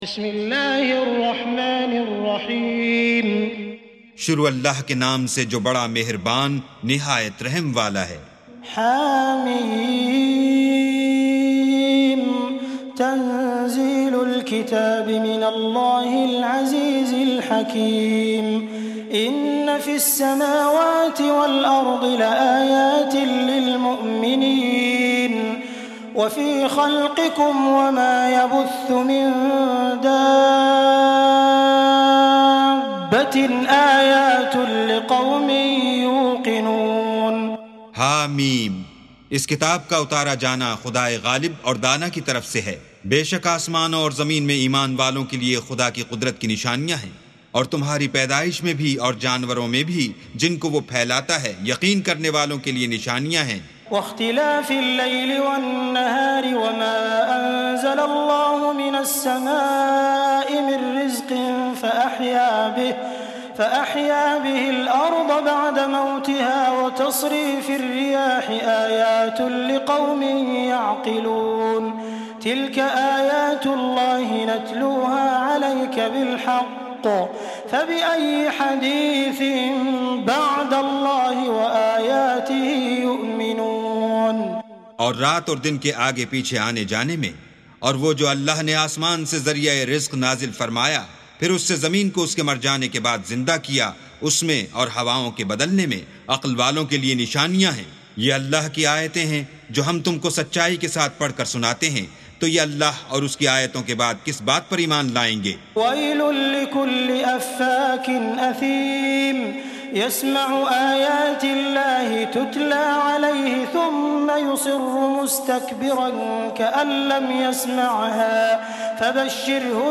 سُورَةُ الجَاثِيَةِ بصوت الشيخ السديس والشريم مترجم إلى الاردو